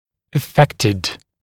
[ə’fektɪd][э’фэктид]страдающий (каким-либо недугом); пораженный (болезнью)